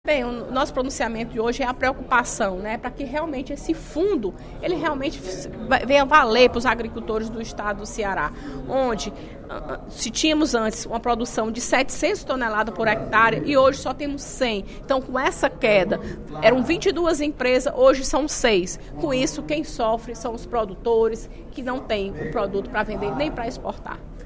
A deputada Fernanda Pessoa (PR) defendeu, em pronunciamento no primeiro expediente da sessão plenária desta quarta-feira (12/03), a recuperação da cultura do caju no Ceará.